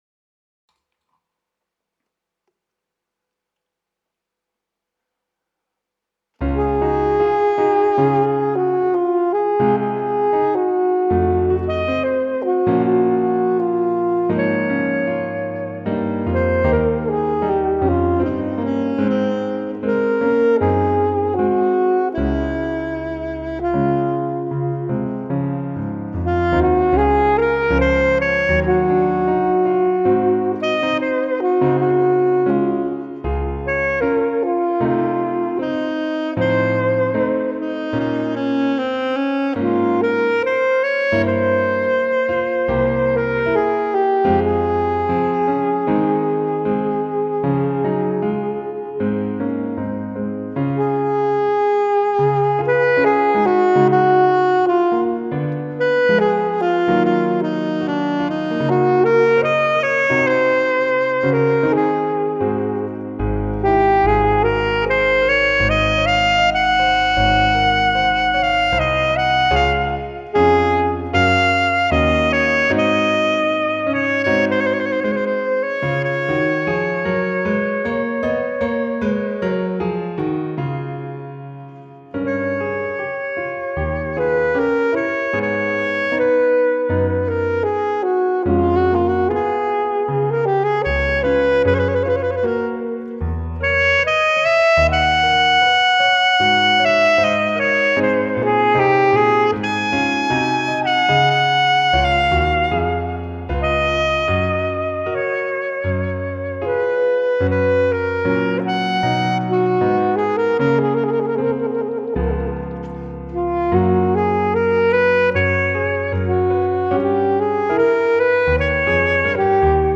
Scored for Alto Sax and Piano